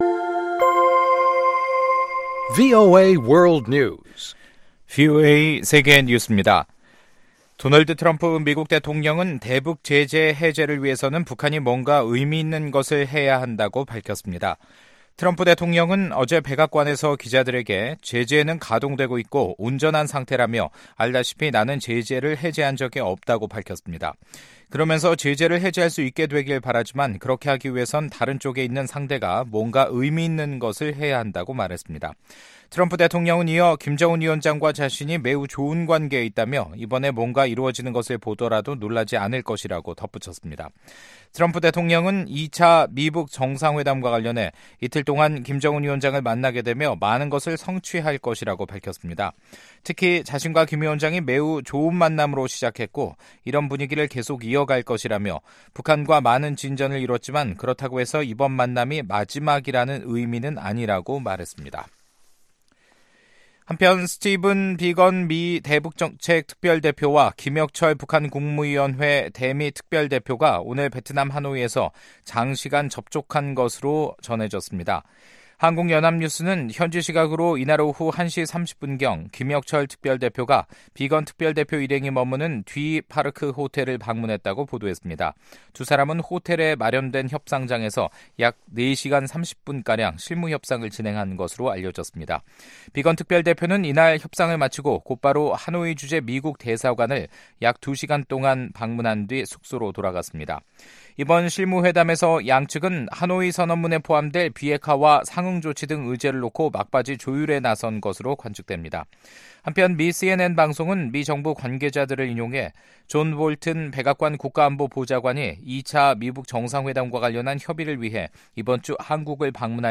VOA 한국어 간판 뉴스 프로그램 '뉴스 투데이', 2019년 2월 21일 3부 방송입니다. 트럼프 대통령은 북한의 비핵화를 바란다면서도 북한이 핵 미사일 실험을 하지 않는 한 서두를 것이 없다고 밝혔습니다. 미국 국무부가 미-북 관계의 근본적인 변화에 대한 기대감을 나타냈습니다.